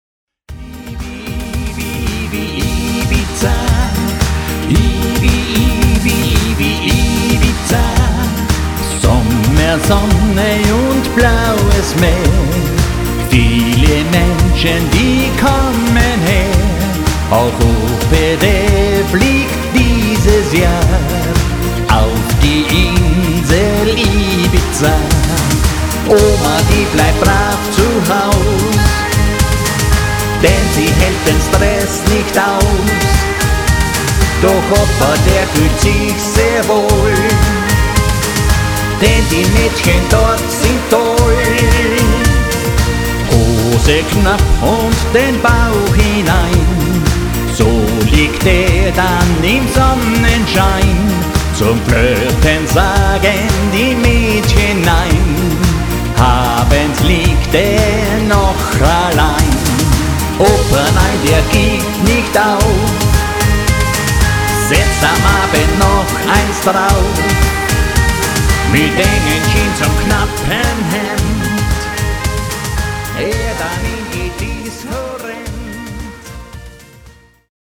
Tanz- und Unterhaltungsmusik